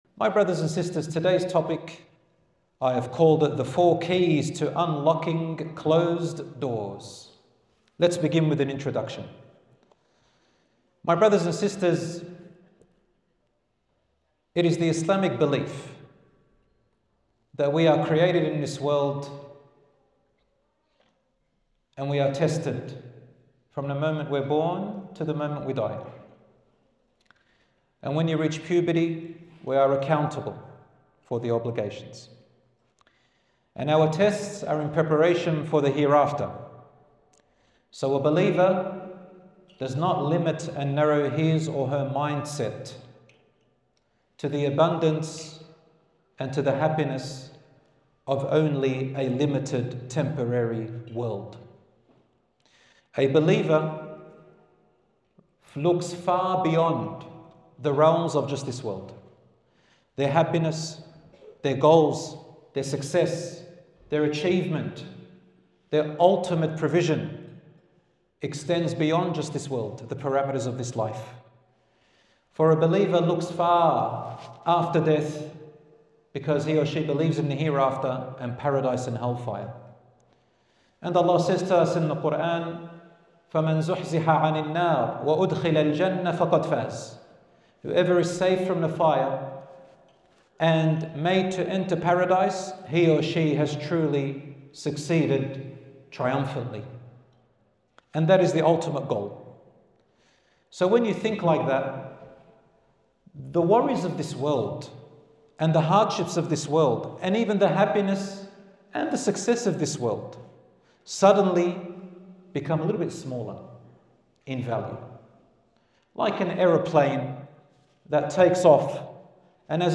In the second leg of my tour in Birmingham (UK), we look at taking practical steps to create a healthy and harmonious family dynamic.